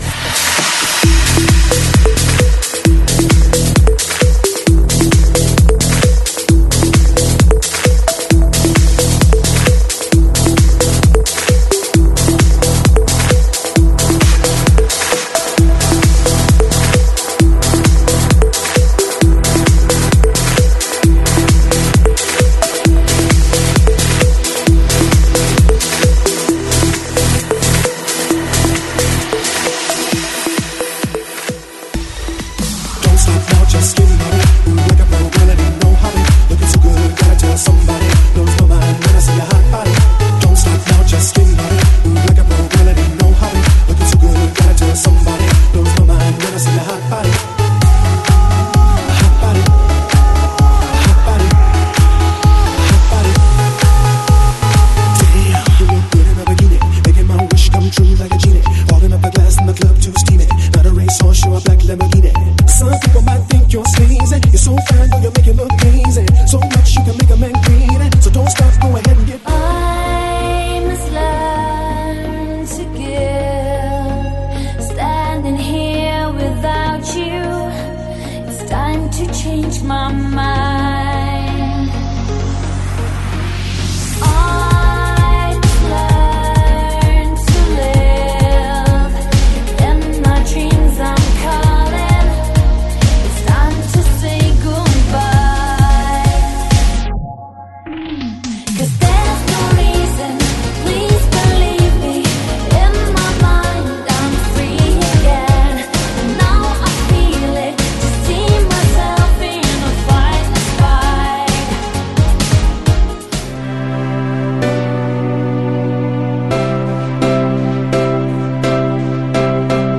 ein Mix aus Dancefloor House, After Work und Urban Pop